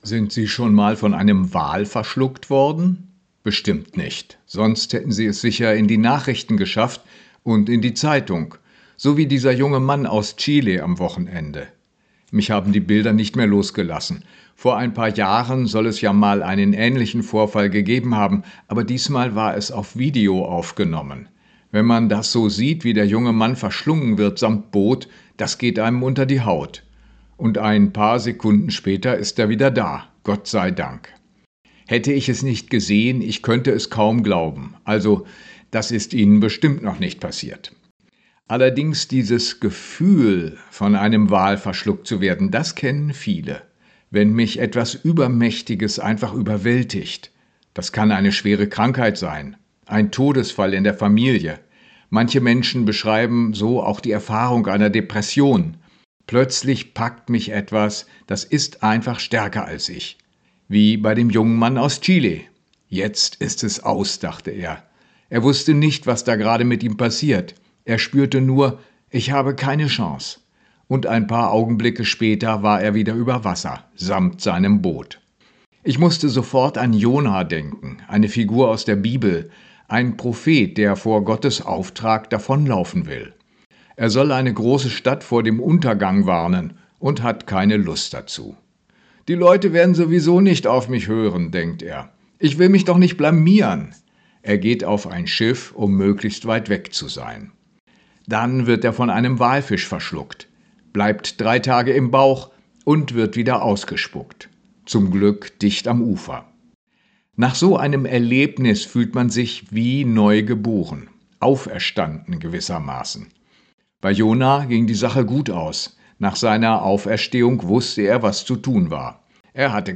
Radioandacht vom 17. Februar